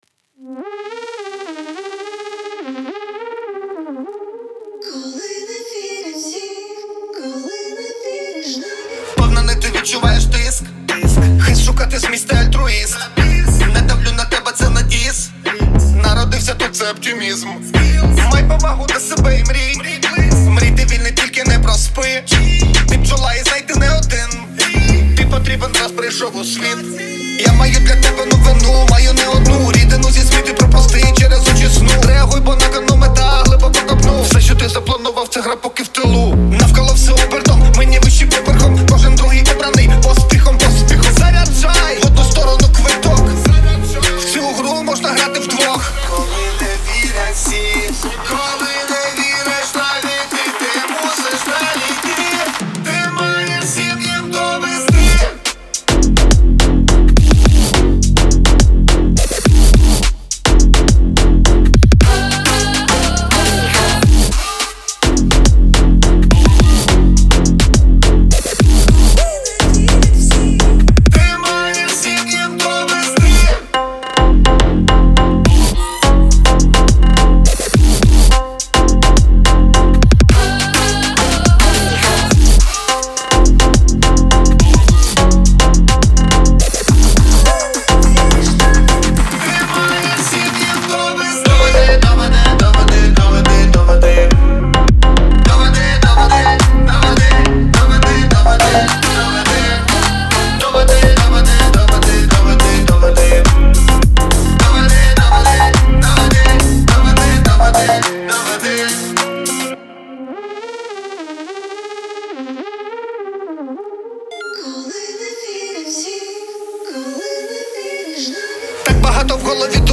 • Жанр: Electronic, Dance, Hip-Hop